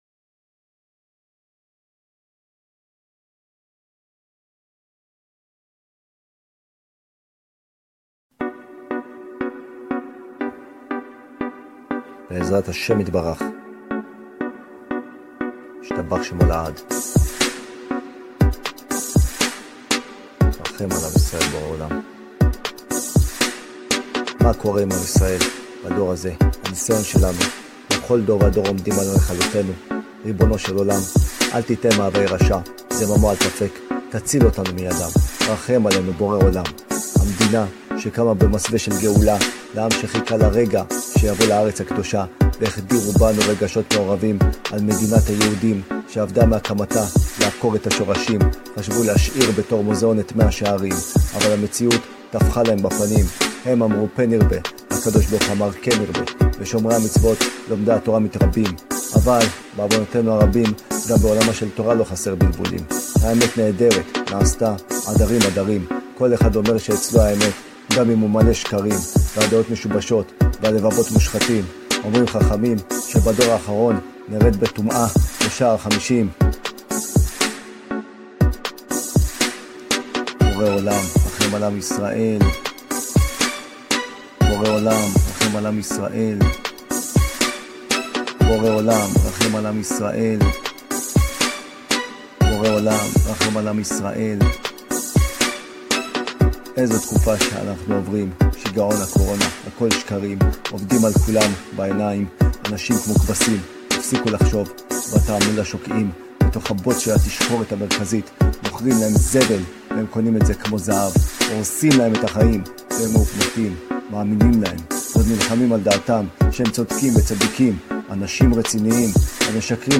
קורונה - שיר מחאה